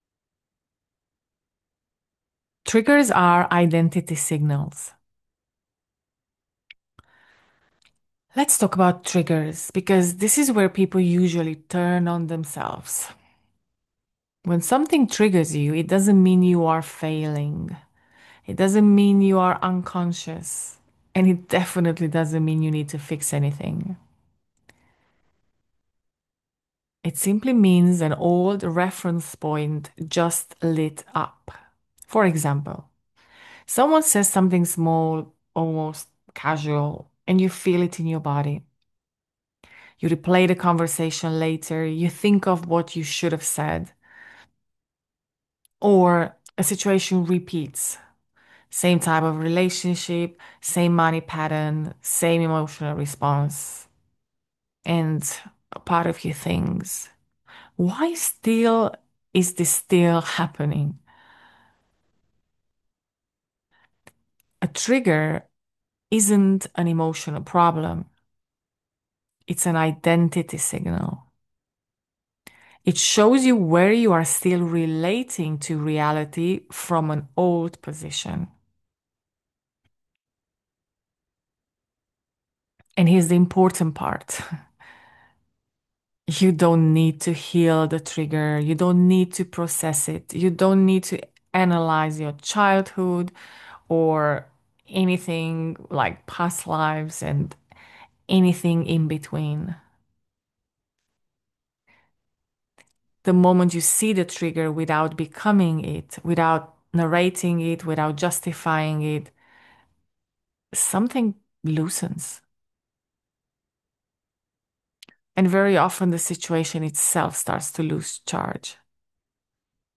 A total of 34 short audios with awareness pauses.